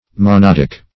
Monodic \Mo*nod"ic\, Monodical \Mo*nod"ic*al\, a. [Gr. ?.]